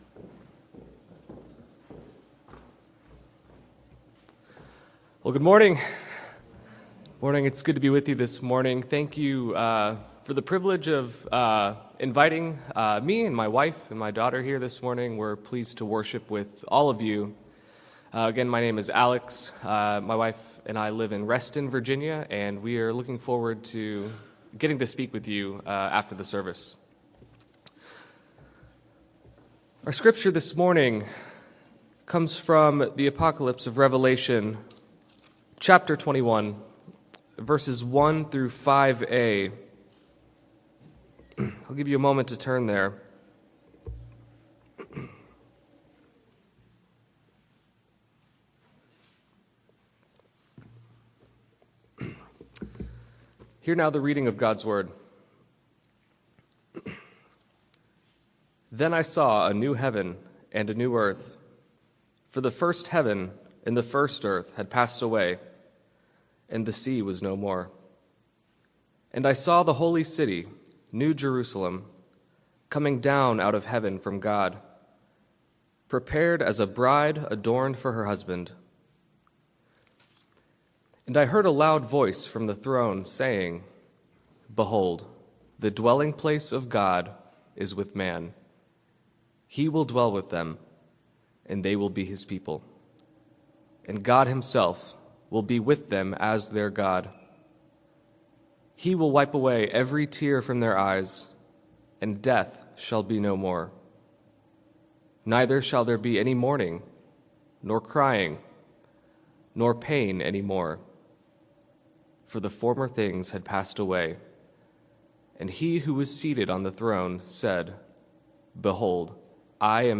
God is Making All Things New: Sermon on Revelation 21:1-5a - New Hope Presbyterian Church